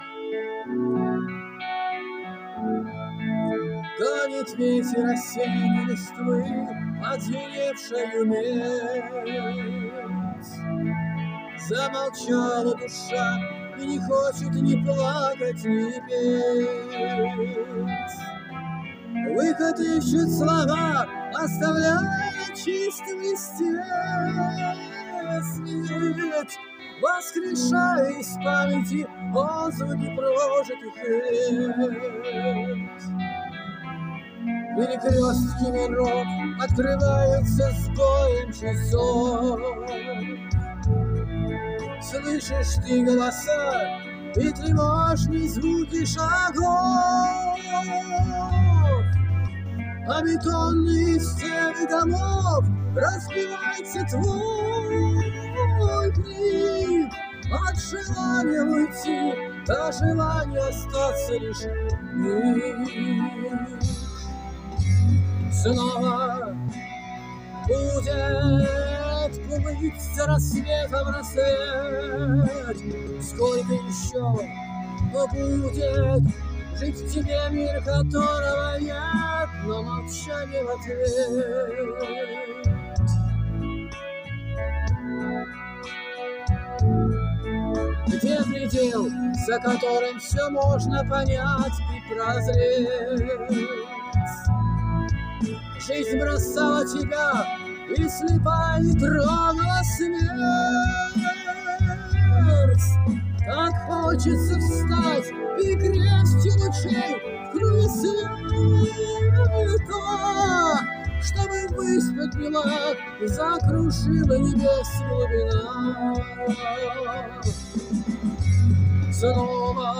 Охрип.🪚 Немного изменяю технику пения.🛠 Как звучит?